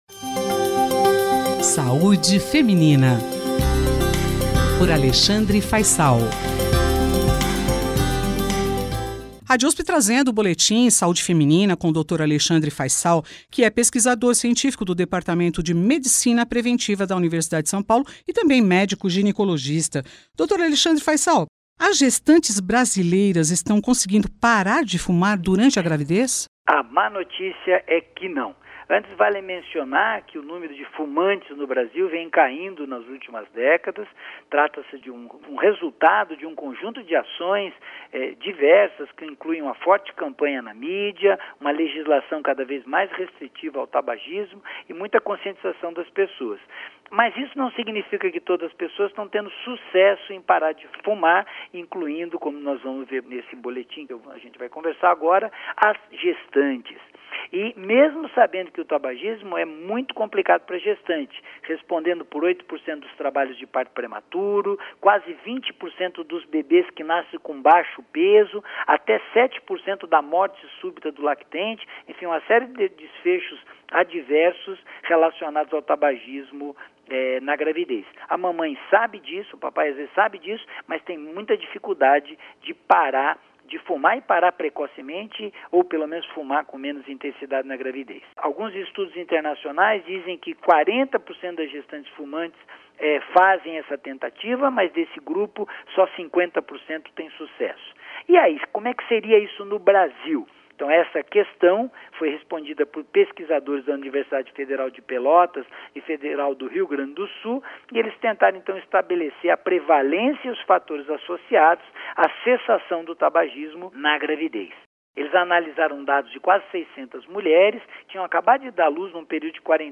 SAUDE-FEMININA-25-ABRIL-CESSAR-DE-FUMAR-NA-GRAVIDEZ.mp3